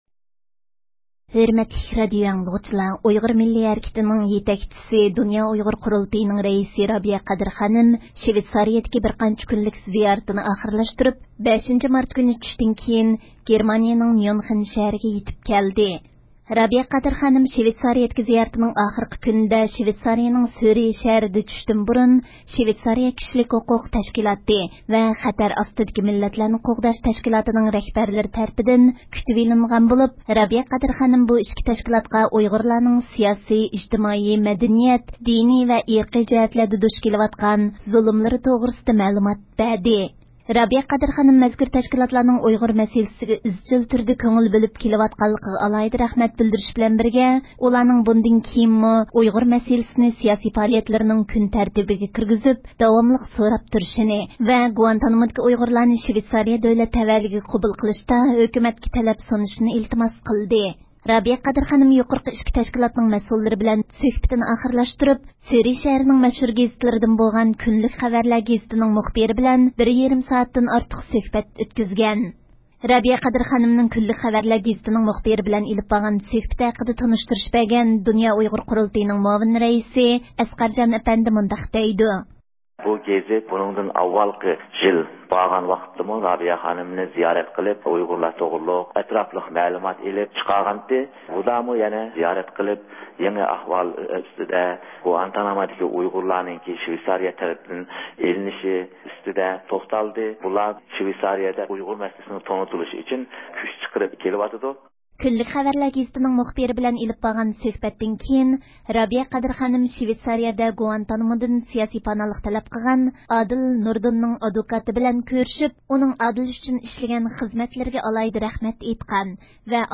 ئۆتكۈزگەن سۆھبىتىمىزنىڭ تەپسىلاتىنى ئاڭلايسىلەر.